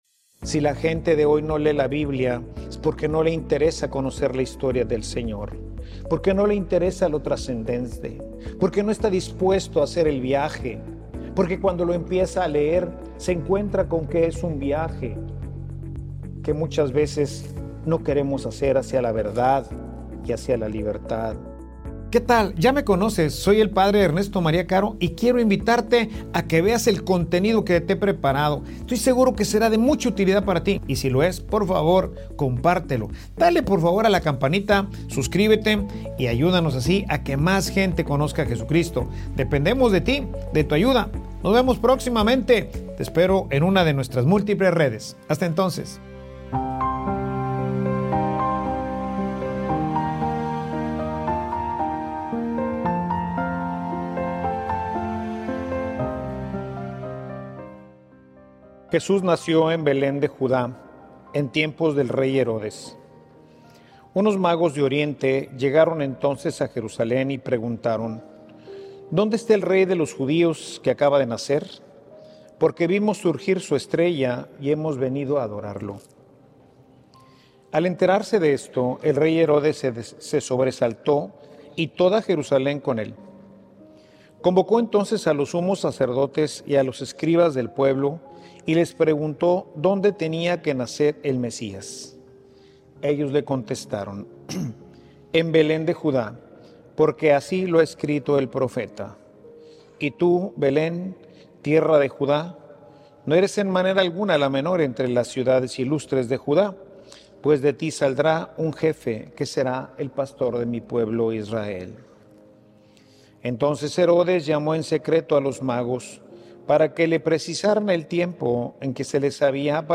Homilia_Si_buscas_la_verdad_la_encontraras.mp3